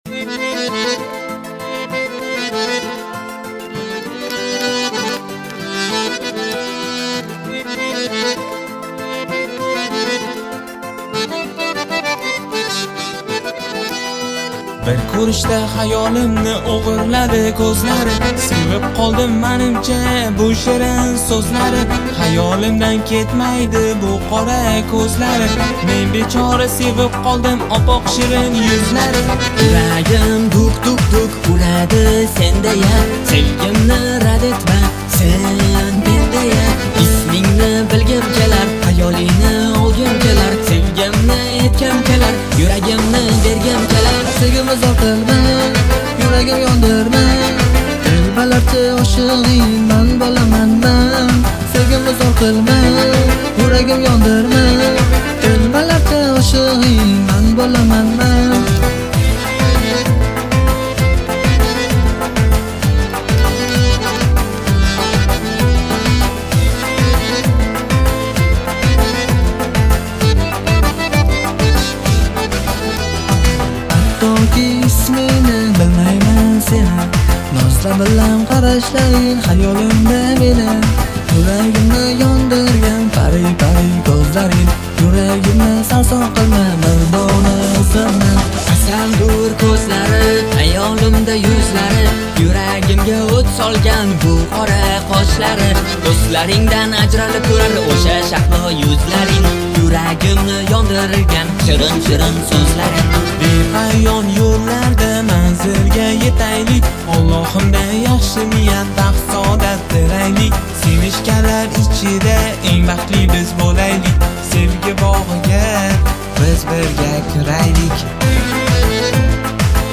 • Жанр: Турецкие песни